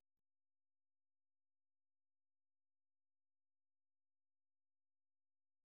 Ей противопоставляется вторая тема, лирическая: